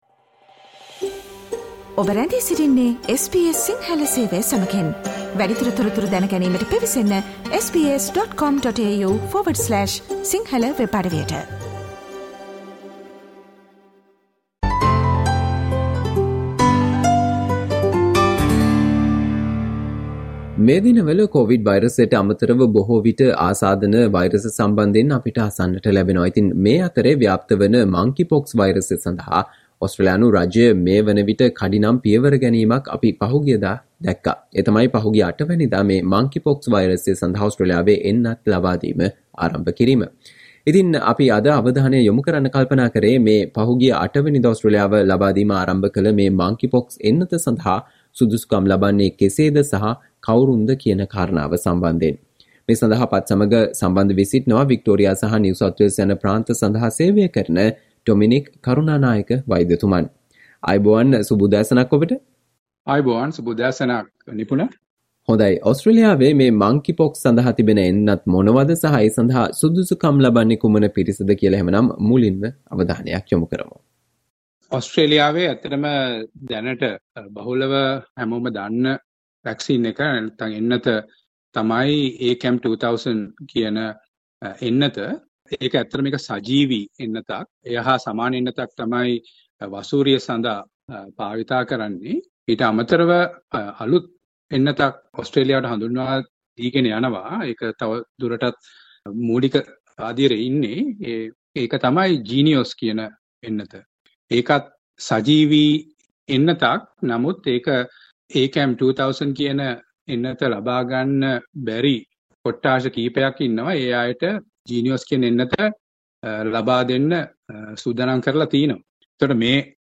අගෝස්තු 8 වනදා ඕස්ට්‍රේලියාවේ ලබාදීම ආරම්භ කල Monkeypox එන්නත සඳහා සුදුසුකම් ලබන්නේ කෙසේද සහ කවුරුන්ද යන්න පිළිබඳව වන සාකච්චාවට සවන්දෙන්න